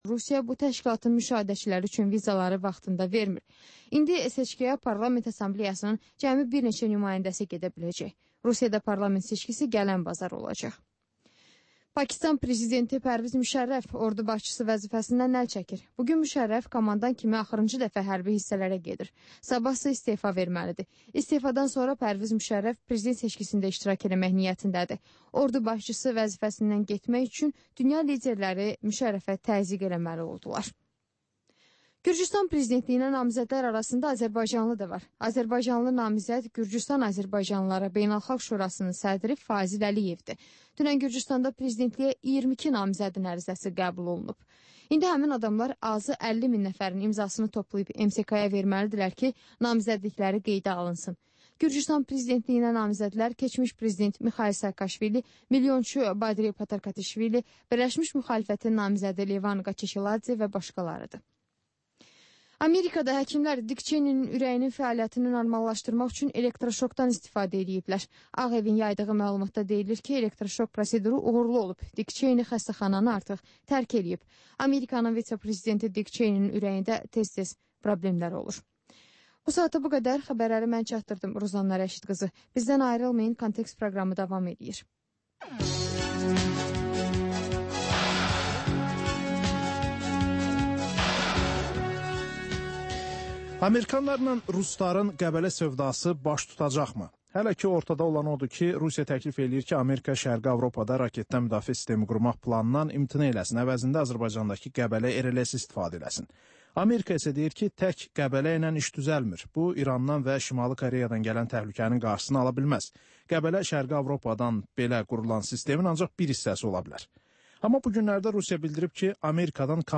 Hadisələrin təhlili, müsahibələr, xüsusi verilişlər.